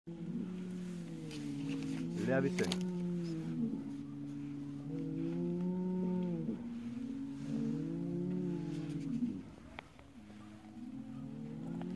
In der Dämmerung wird es richtig laut auf den Weiden. Die Stiere fordern sich gegenseitig zu Rangkämpfen auf und berichten sich von den „Ereignissen des Tages“.